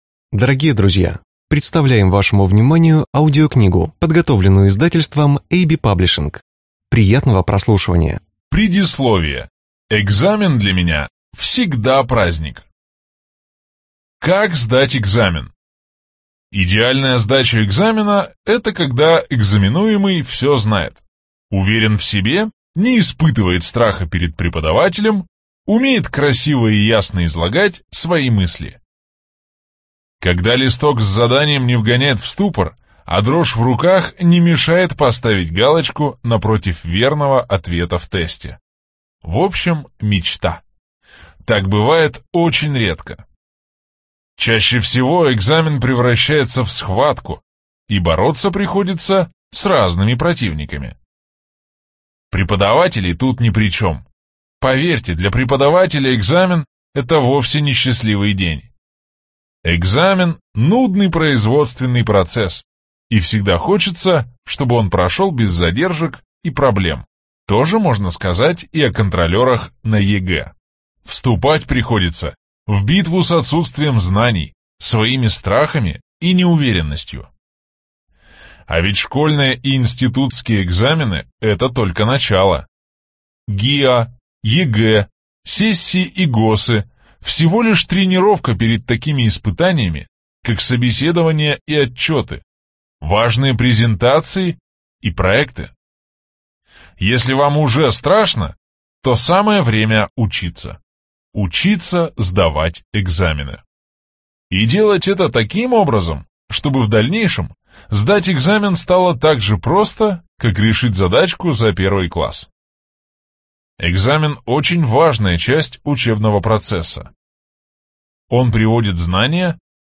Аудиокнига Как сдавать экзамены. Практическое руководство | Библиотека аудиокниг